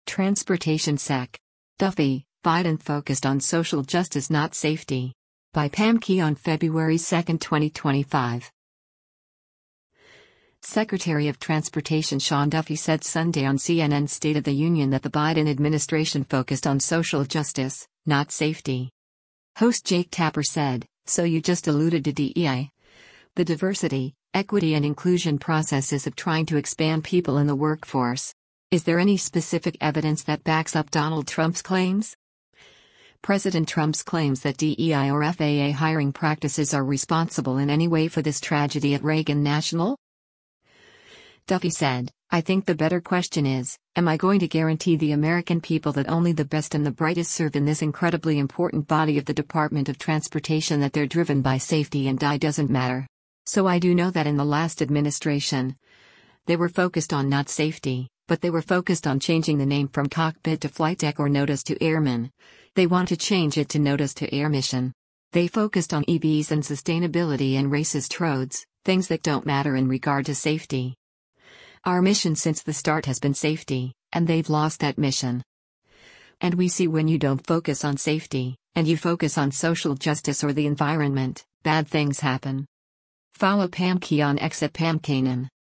Secretary of Transportation Sean Duffy said Sunday on CNN’s “State of the Union” that the Biden administration focused on social justice, not safety.